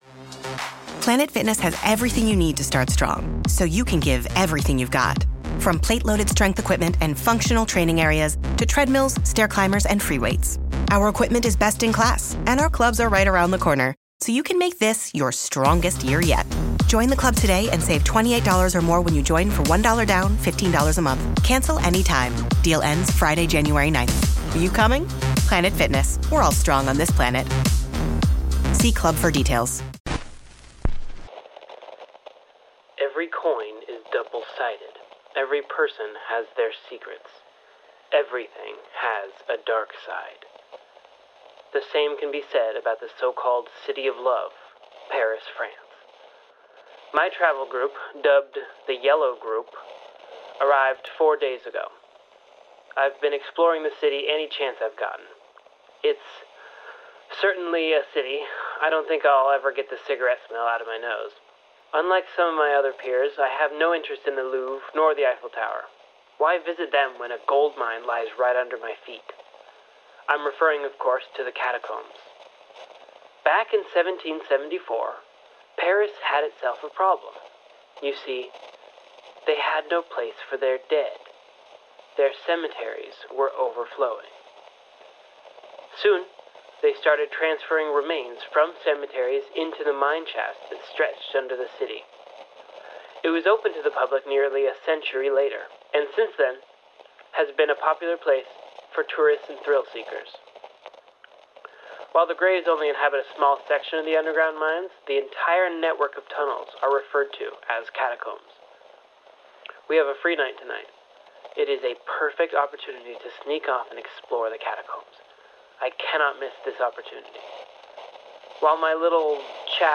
loud sounds warning static warning
bass warning